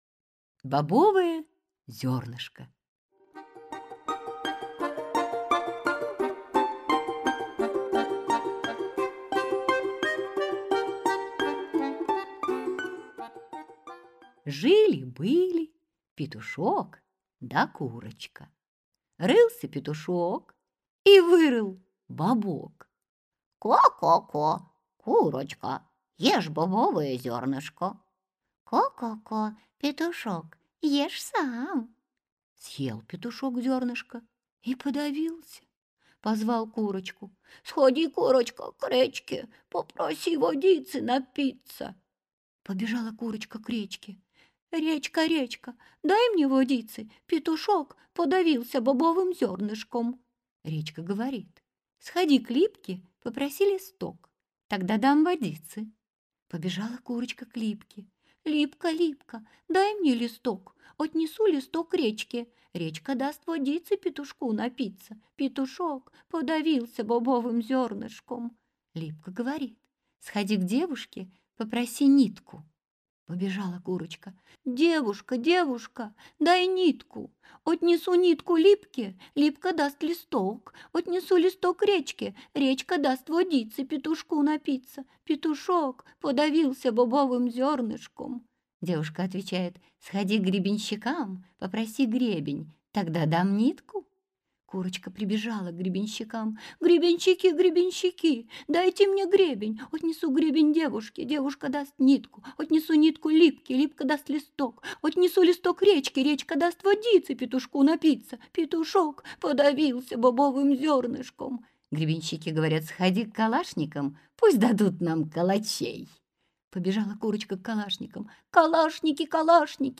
Аудиокнига Любимые русские сказки | Библиотека аудиокниг